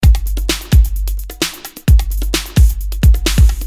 Electro rythm - 130bpm 27